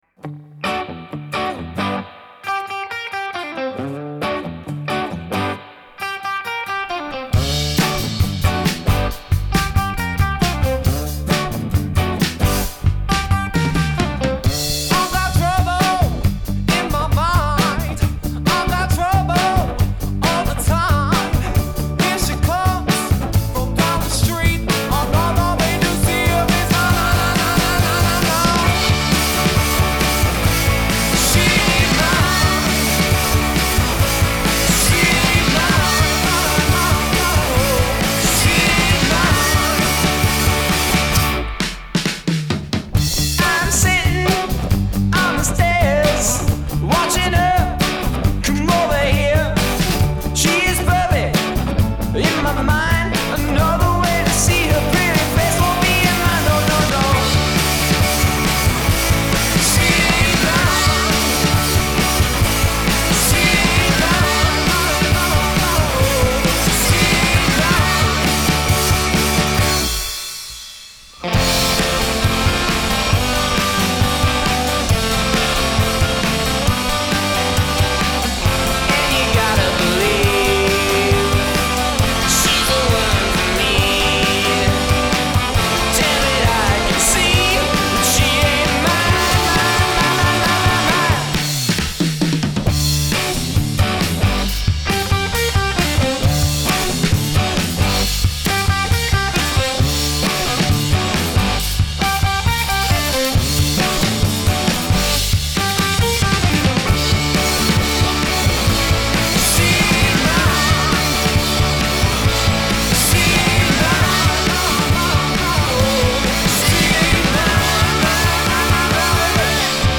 Genre: Rock 'n' Roll, Soul